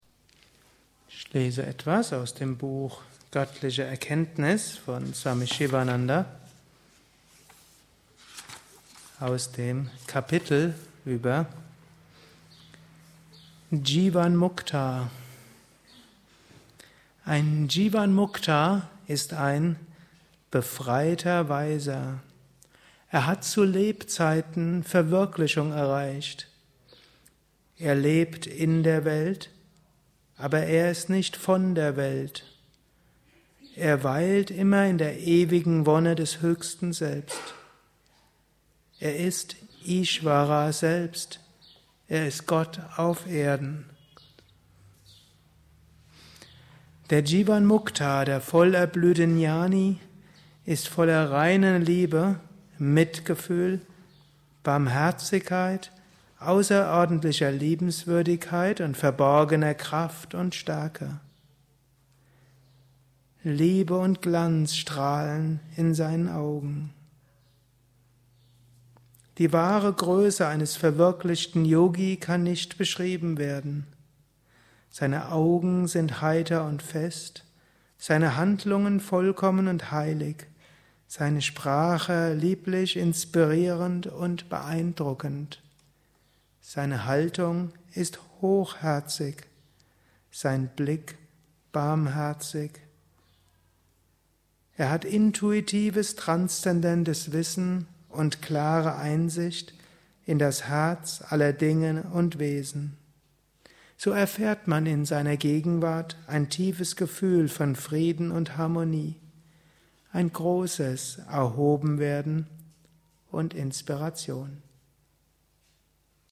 Kurzvorträge
gehalten nach einer Meditation im Yoga Vidya Ashram Bad Meinberg.